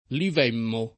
[ liv $ mmo ]